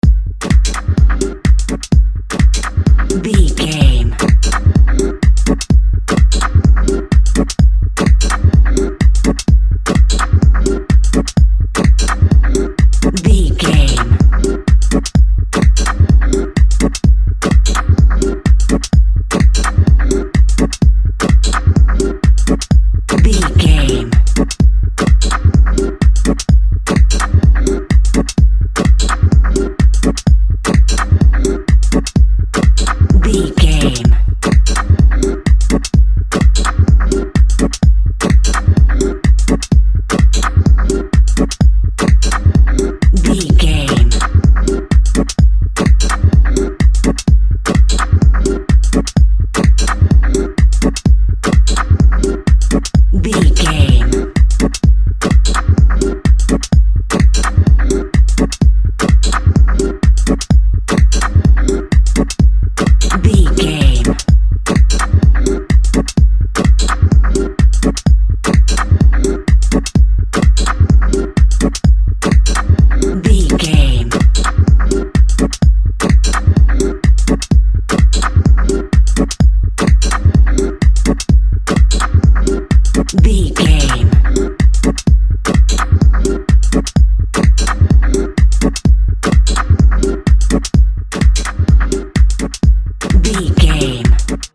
Chilling Dance Music Cue.
Atonal
uplifting
futuristic
hypnotic
dreamy
tranquil
smooth
drum machine
synthesiser
Drum and bass
break beat
electronic
sub bass
synth lead